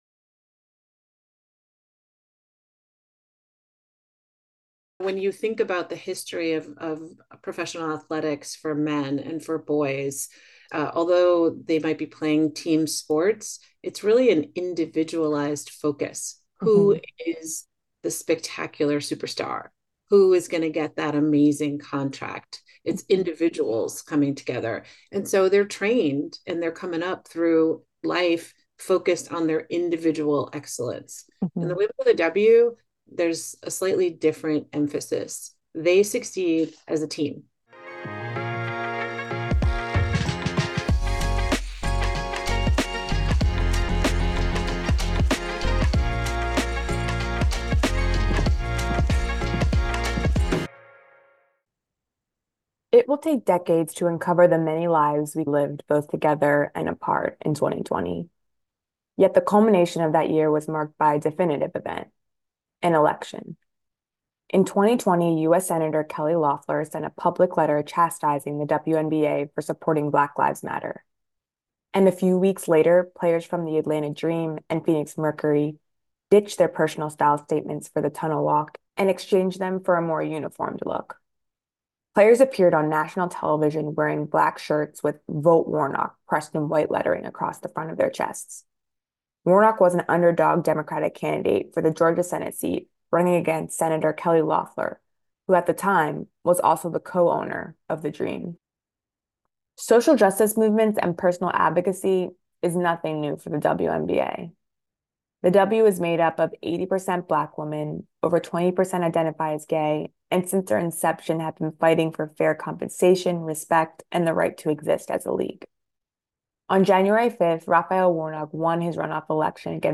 In light of the league’s continued rise, I'm re-airing this conversation with filmmaker Dawn Porter about her documentary The Power of the Dream, which captures the WNBA’s pivotal 2020 season.